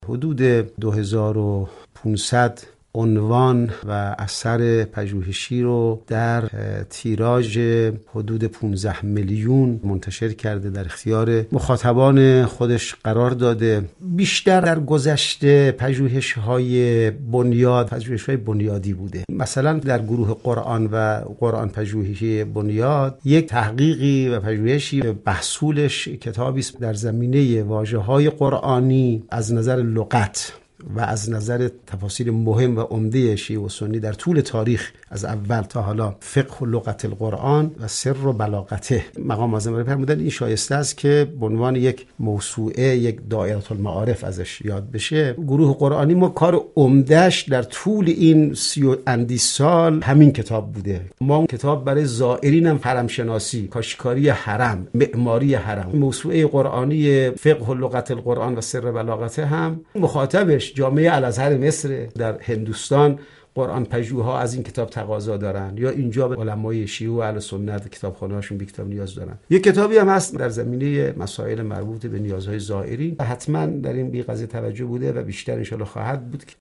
گفتگوی ویژه خبری رادیو زیارت